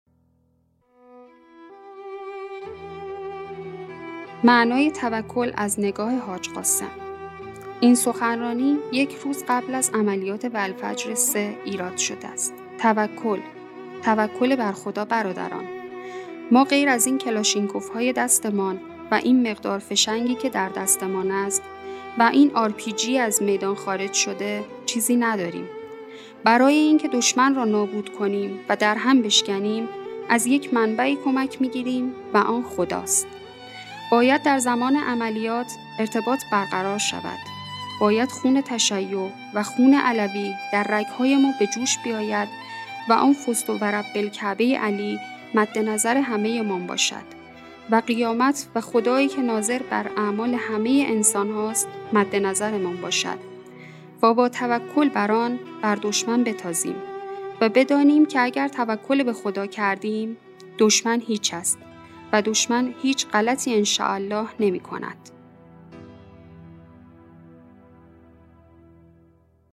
این سخنرانی یک روز قبل از عملیات والفجر3 توسط قسم سلیمانی ایراد شده است